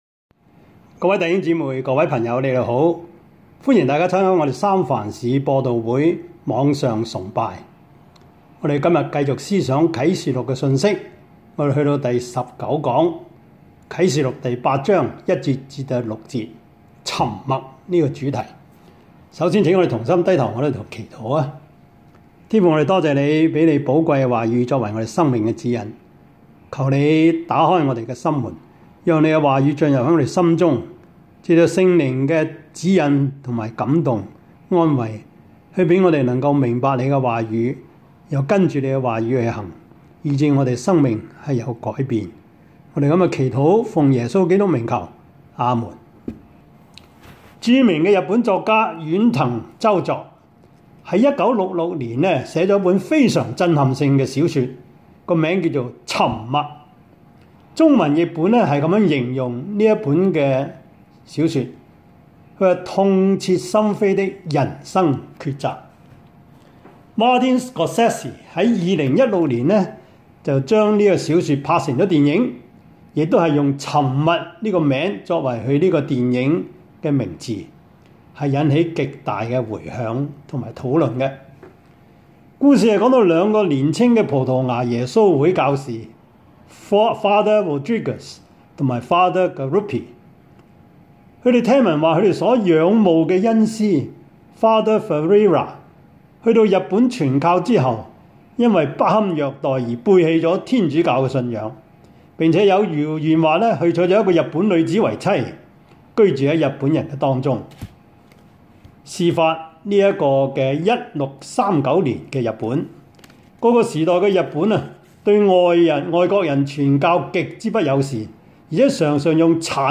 Service Type: 主日崇拜
Topics: 主日證道 « 第二十一課:中世紀的教會 耶穌身邊的四種人 »